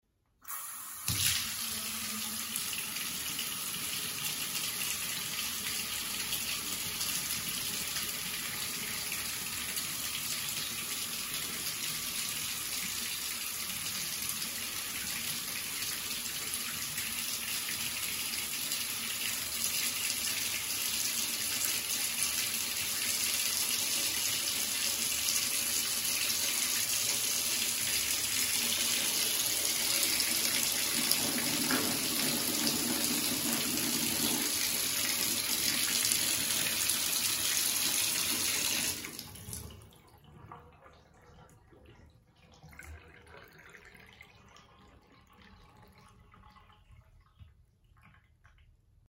Звуки водяного насоса
На этой странице собраны звуки работы водяных насосов разных типов.